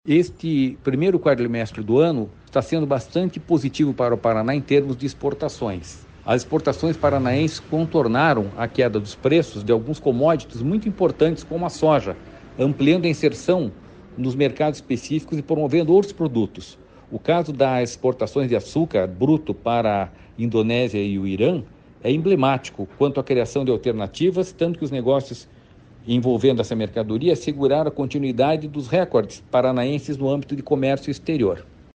Sonora do diretor-presidente do Ipardes, Jorge Callado, sobre as exportações do Paraná no primeiro quadrimestre de 2024